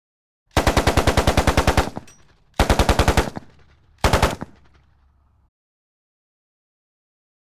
Chopper.wav